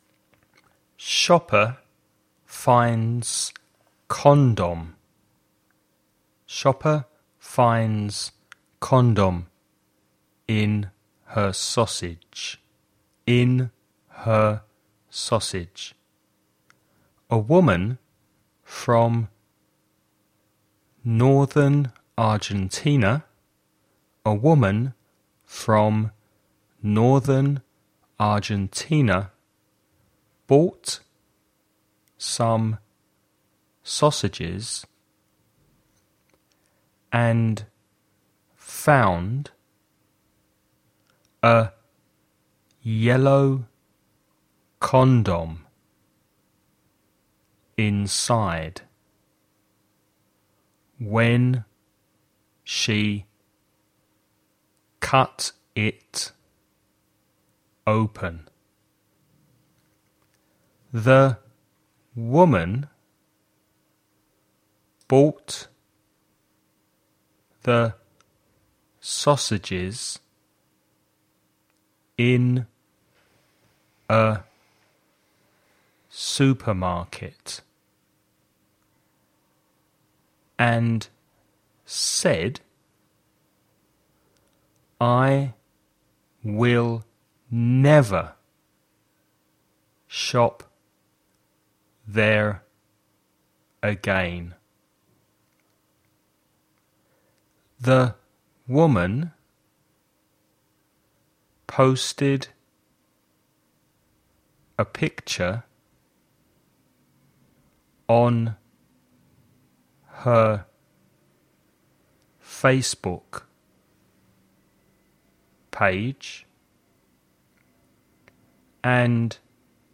DICTATION
Curso de Inglés en audio Mansionauto 1. Listen to the text read at normal speed.